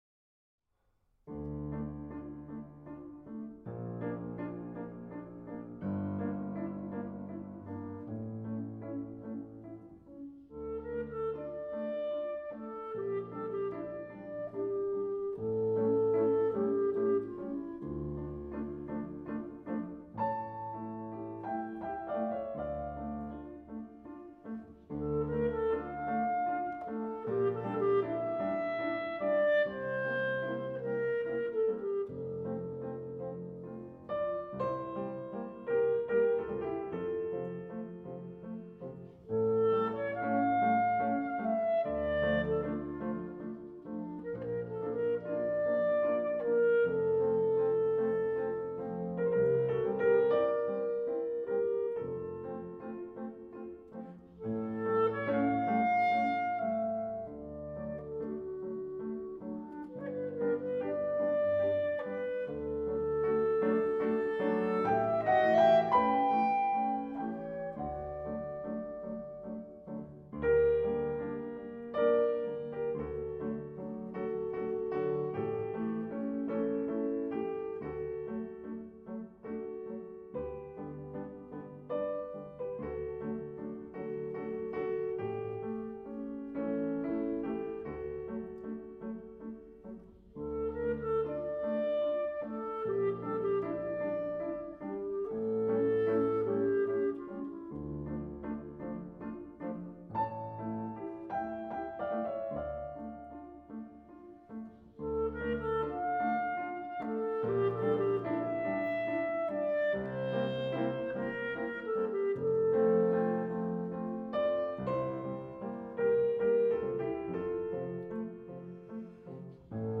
clarinet.
piano.